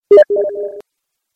Steam Notification
Steam-Notification.mp3